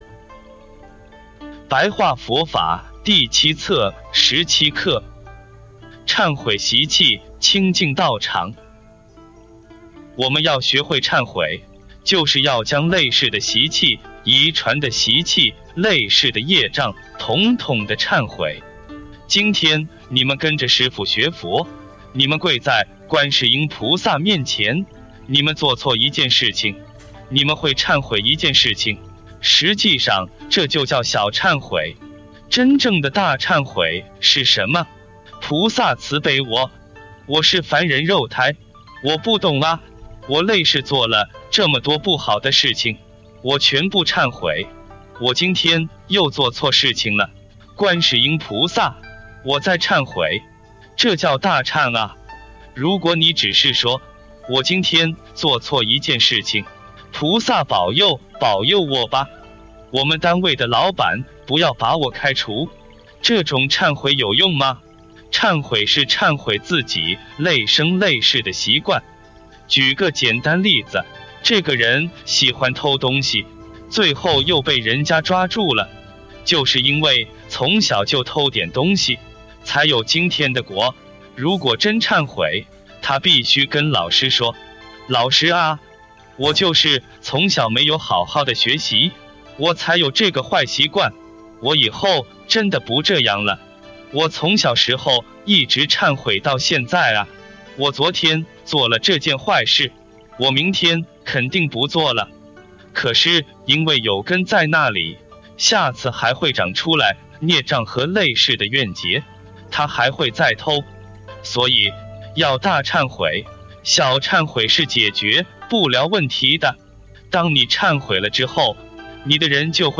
2013年觀音堂開示 法界心